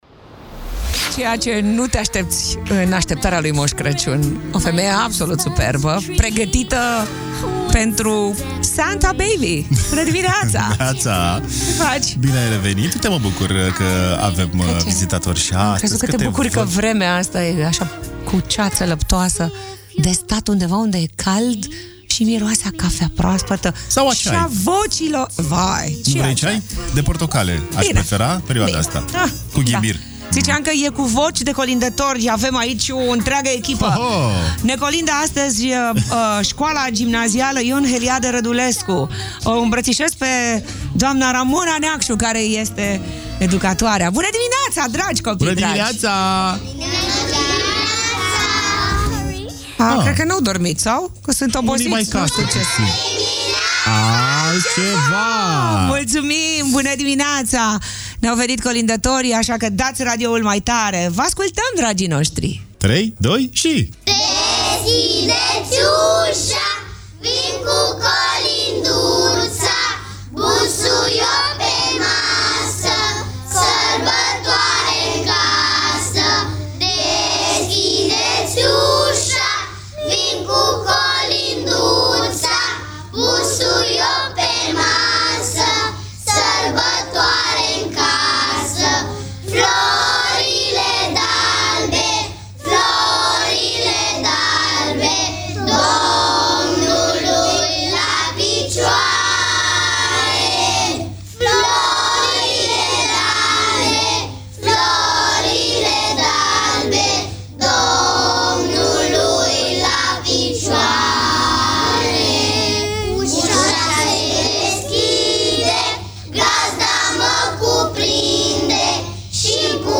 Magic Morning - 18 Decembrie - colindători, Copiii de la Școala Gimnazială „Ion Heliade Rădulescu”